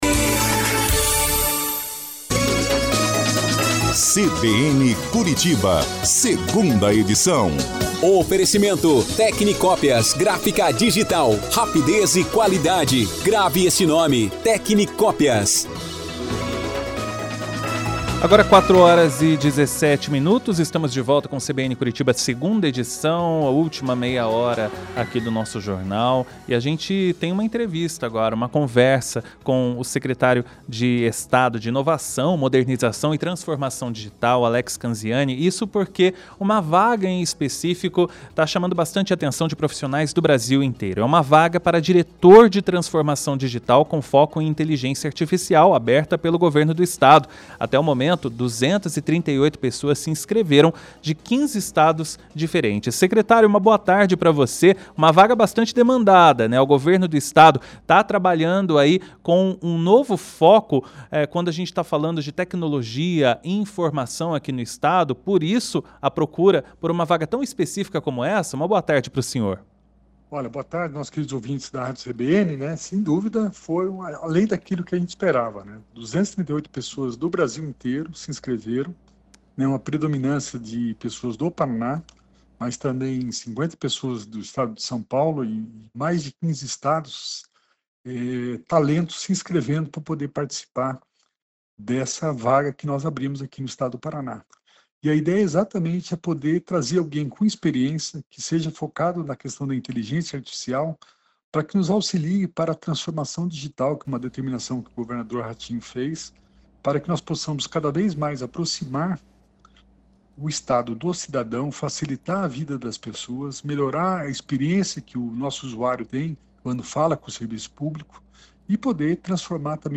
O secretário de Inovação, Modernização e Transformação Digital do Paraná, Alex Canziani, conversou ao vivo no CBN Curitiba 2ª Edição desta terça-feira (9) para falar sobre a alta procura de interessados para a vaga de diretor de Transformação Digital com foco em Inteligência Artificial (IA).